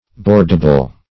Meaning of boardable. boardable synonyms, pronunciation, spelling and more from Free Dictionary.
Boardable \Board"a*ble\, a. That can be boarded, as a ship.